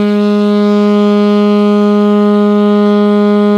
ALTO  PP G#2.wav